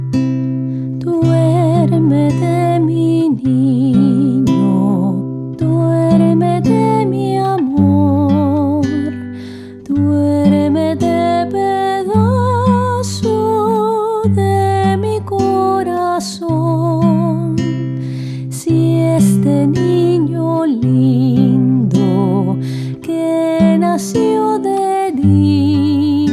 lullabies
in a clear, lilting soprano